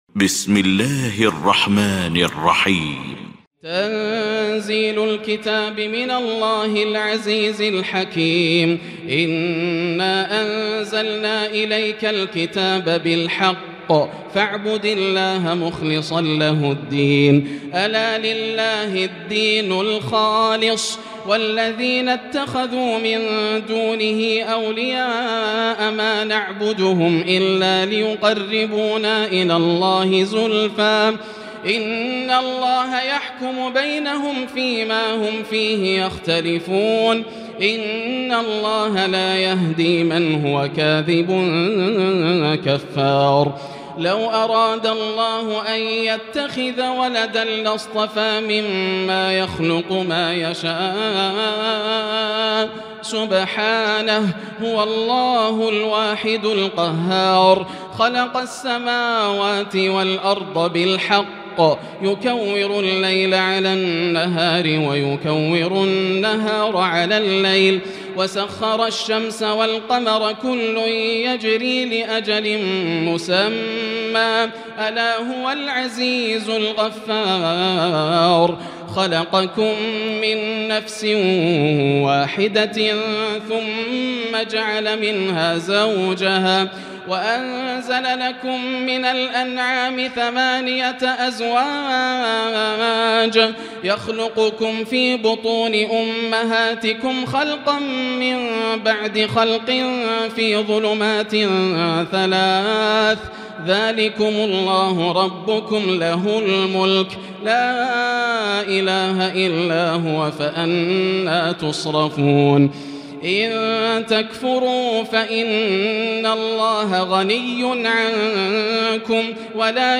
المكان: المسجد الحرام الشيخ: معالي الشيخ أ.د. عبدالرحمن بن عبدالعزيز السديس معالي الشيخ أ.د. عبدالرحمن بن عبدالعزيز السديس فضيلة الشيخ عبدالله الجهني فضيلة الشيخ ياسر الدوسري الزمر